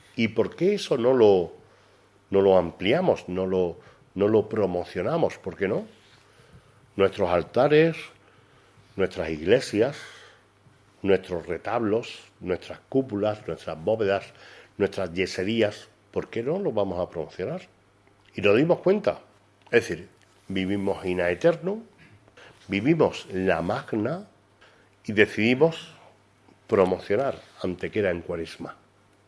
El alcalde de Antequera, Manolo Barón, y la teniente de alcalde delegada de Cultura y Tradiciones, Elena Melero, han presentado esta tarde en rueda de prensa "Cuaresma en Antequera", un pionero programa de actividades culturales que, promovidas y organizadas por el Ayuntamiento, se desarrollarán a lo largo de toda la Cuaresma con el objetivo de promocionar nuestra ciudad no sólo en Semana Santa, sino también en el período previo a la misma.
Cortes de voz